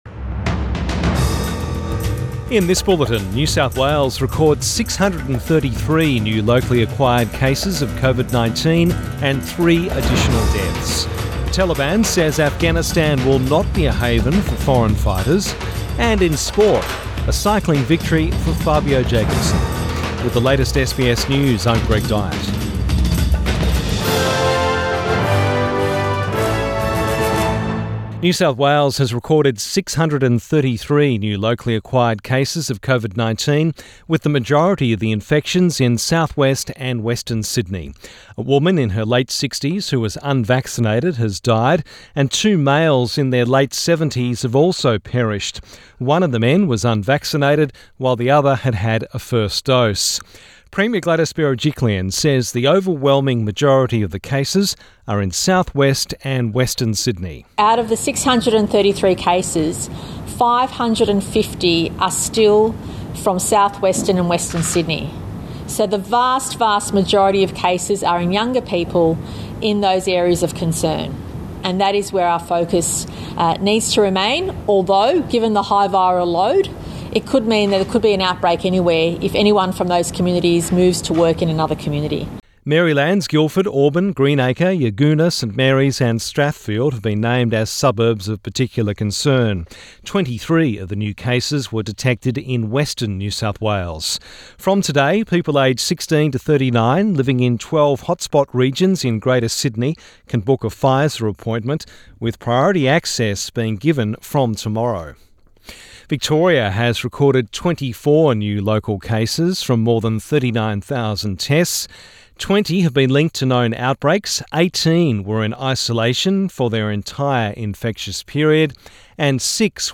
Midday bulletin 18 August 2021